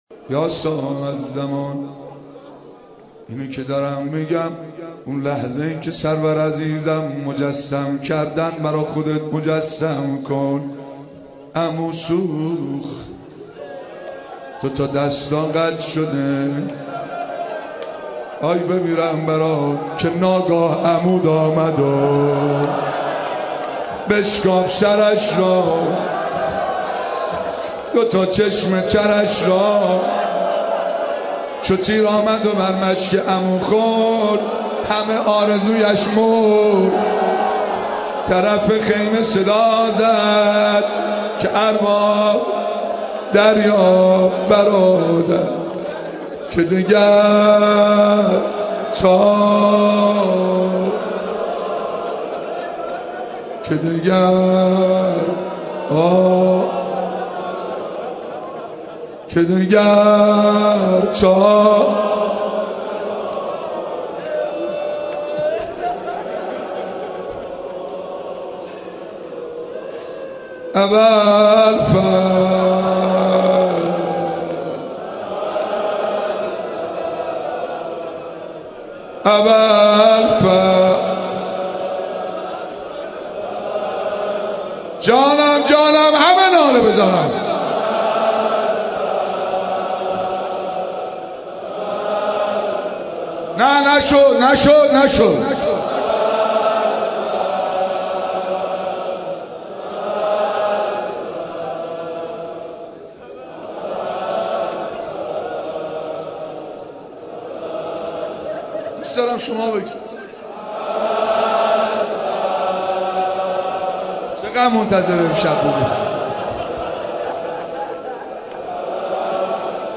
۱۱۲ مطلب با کلمه‌ی کلیدی «متن روضه» ثبت شده است - مرکز نشر و دانلود متن روضه ، صوت روضه، کلیپ روضه، کتاب های مقتل ،شعر، مداحی و غیره